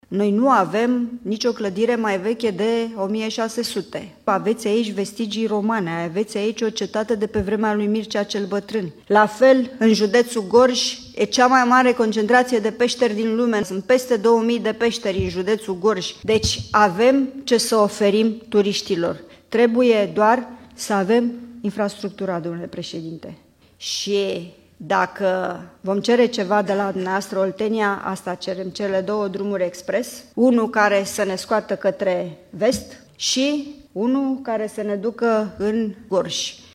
Aflată la conferința județeană a PSD Mehedinți, Olguța Vasilescu și-a exprimat speranța că și alți colegi de partid vor învăța să dezvolte turismul și că, în județe precum Mehedinți și Gorj, vor fi construite mai multe hoteluri capabile să atragă turiști.